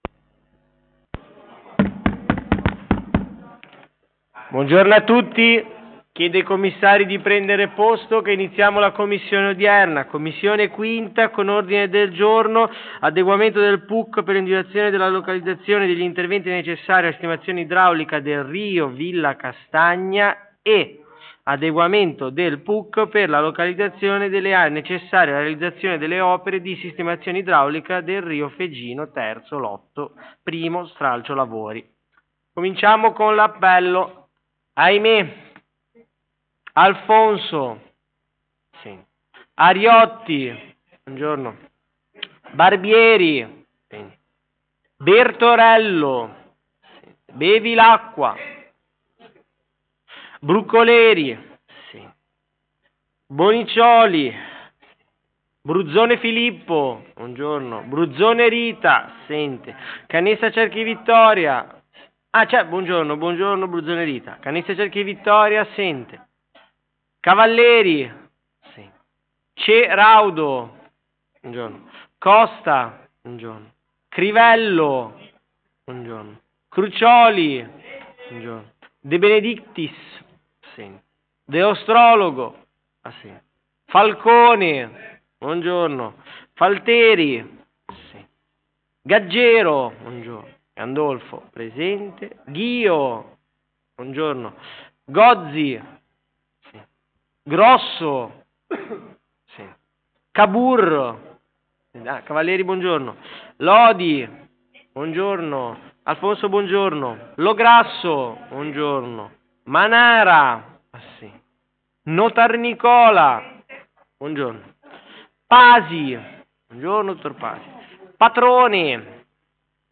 Commissione consiliare o Consiglio Comunale: 5 - Territorio e Promozione delle Vallate
Luogo: presso la sala consiliare di Palazzo Tursi - Albini